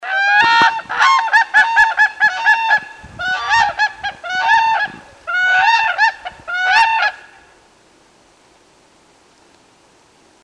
Unison calls are made by both the male and female pair-bonded cranes as they call in unison with each other.
These loud calls are also used by crane pairs to help defends their territory.
Whooping Crane
Unison.mp3